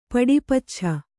♪ paḍi paccha